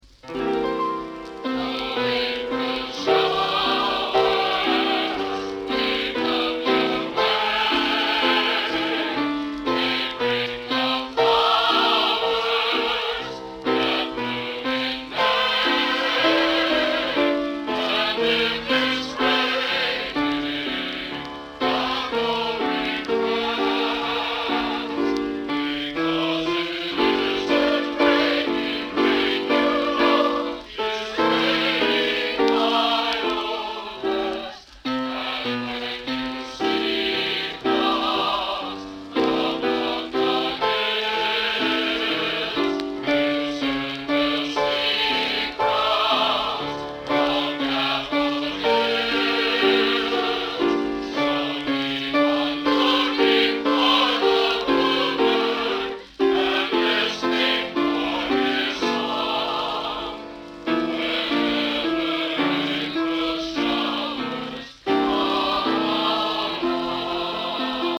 Genre: Popular / Standards | Type: Studio Recording